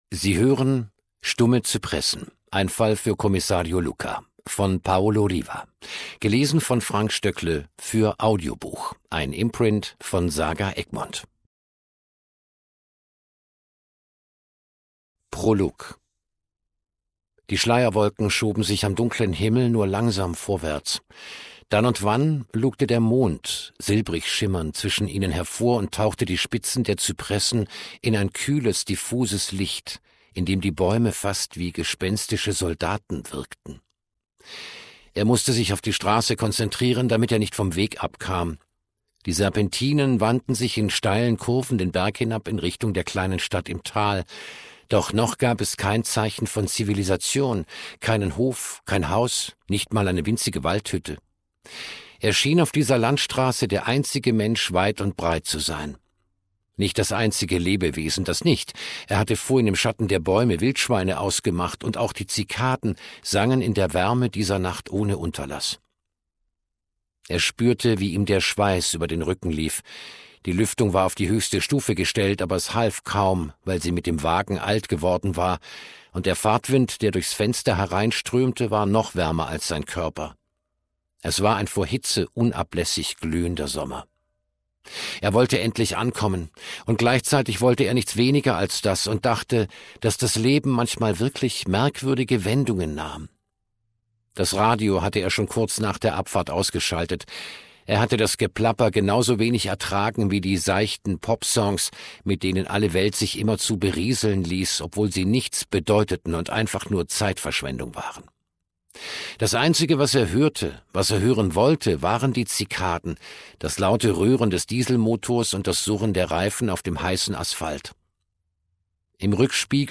2025 | 1. Auflage, Ungekürzte Lesung